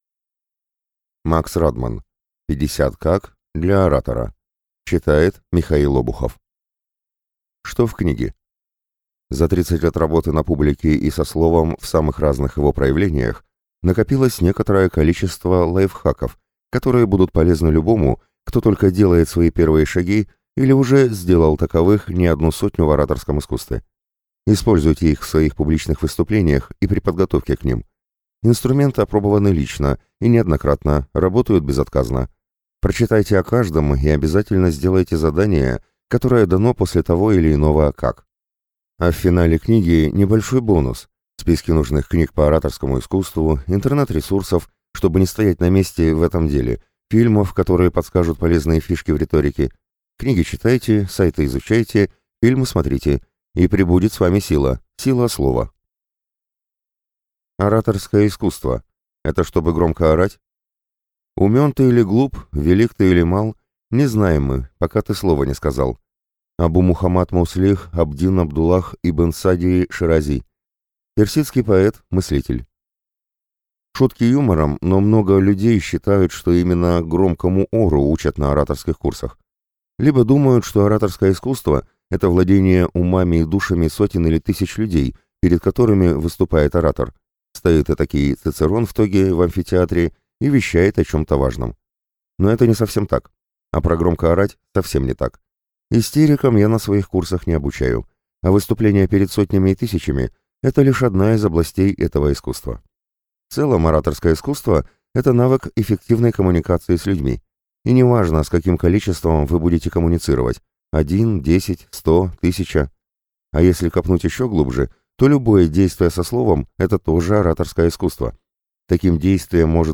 Аудиокнига 50 «как?» для оратора | Библиотека аудиокниг